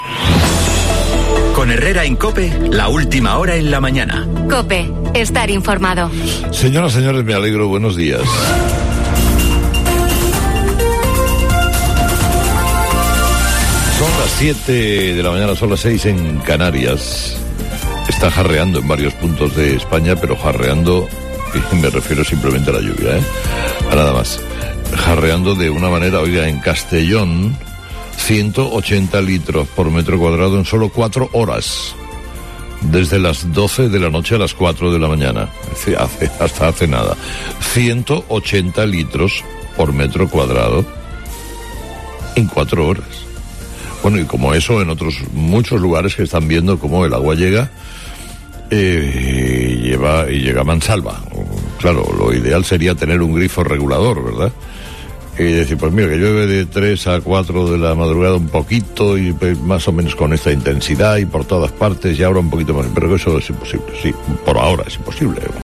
Carlos Herrera destaca, en su apertura en Herrera en COPE, los 180 l/m2 registrados en Castellón